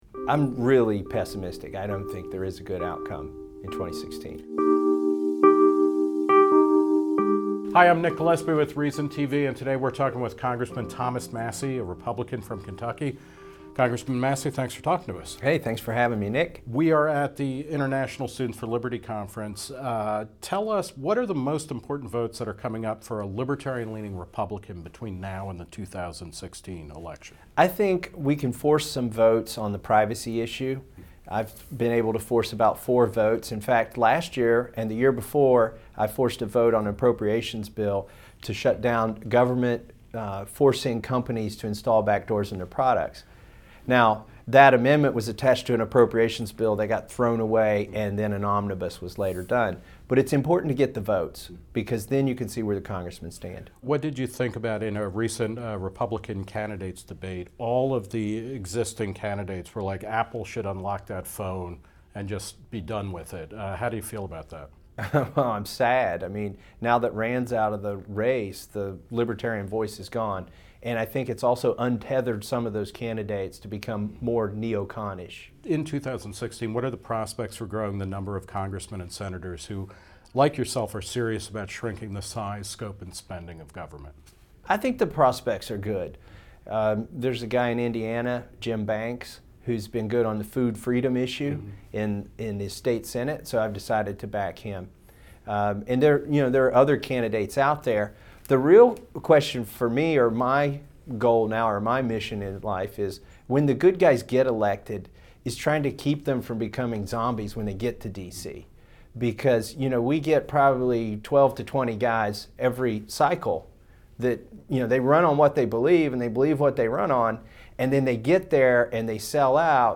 Nick Gillespie sat down with the three-term congressman during the International Students for Liberty Conference to discuss the 2016 election, Apple vs. surveillance state, and why "engineers, not politicians" keep him optimistic about the future.